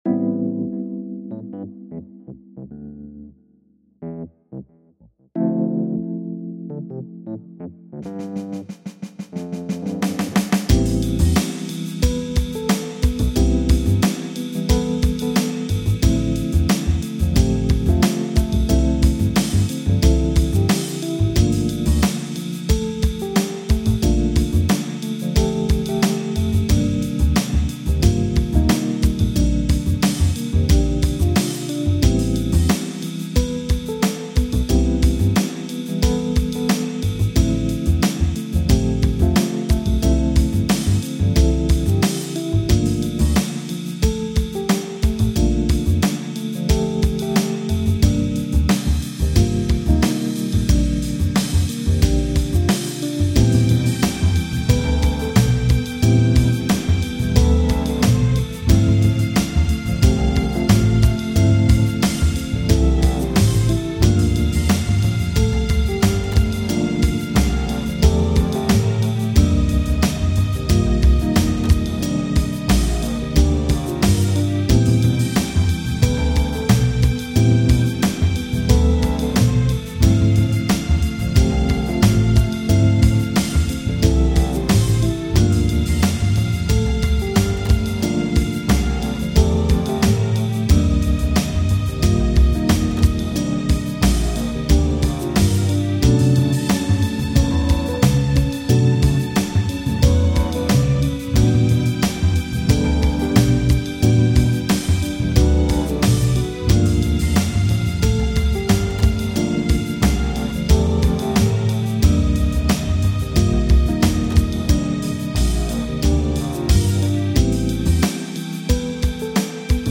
aussenjam #33 - Backingtrack mit leiserem Bass
aussenjam33_backingtrack_less_bass.mp3